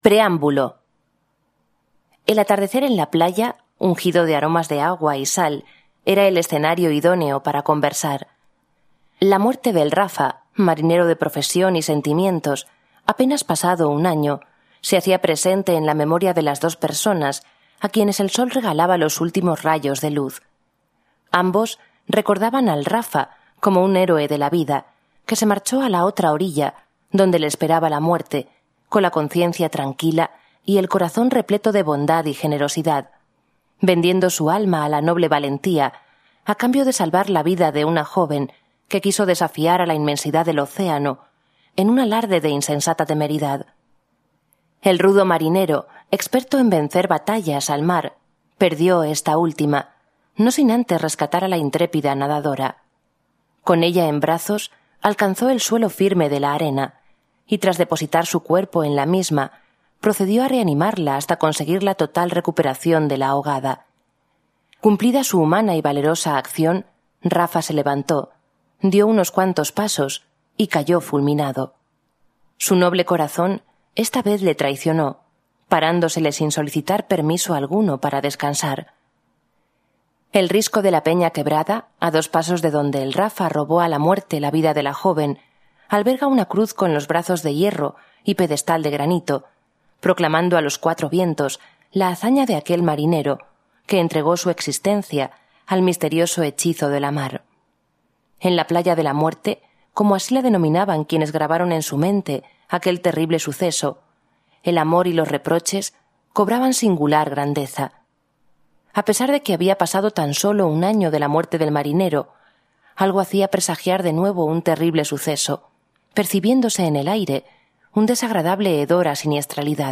Audiolibro realizado por la Fundación ONCE